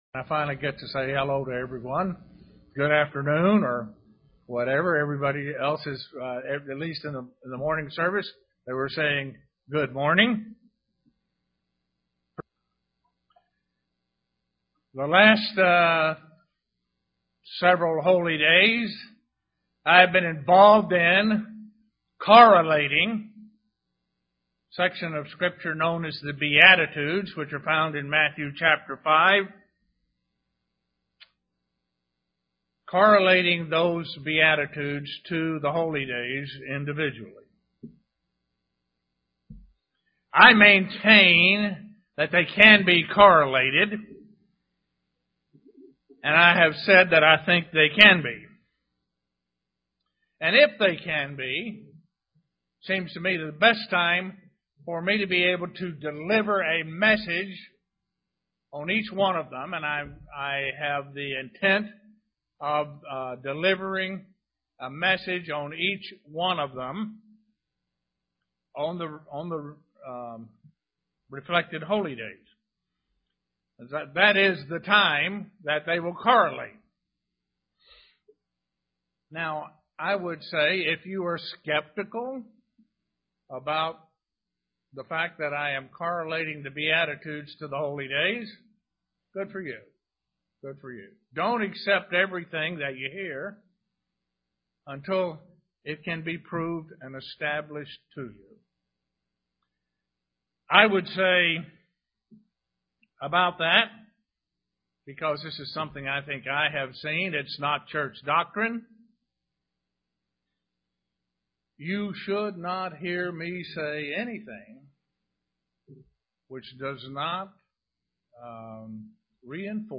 Print How the fourth Beatitude relates to Pentecost UCG Sermon Studying the bible?